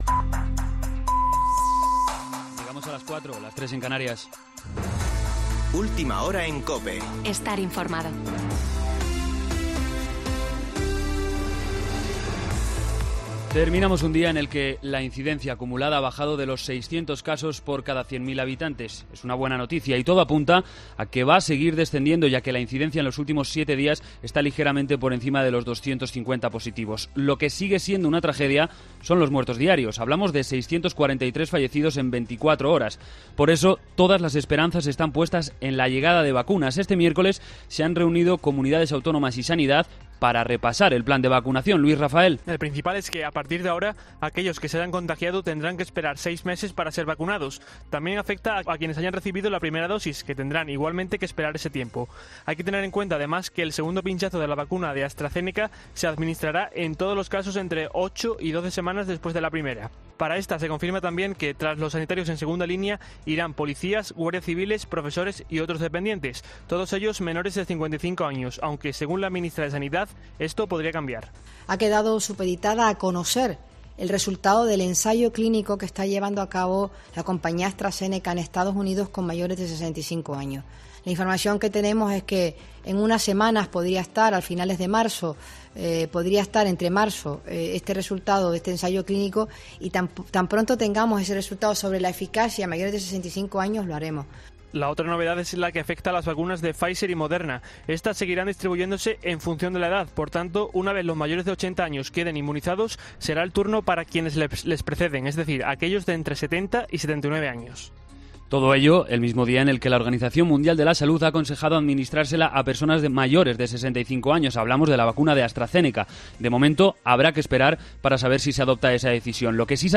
Boletín de noticias COPE del 11 de febrero de 2021 a las 04.00 horas